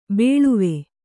♪ bēḷuve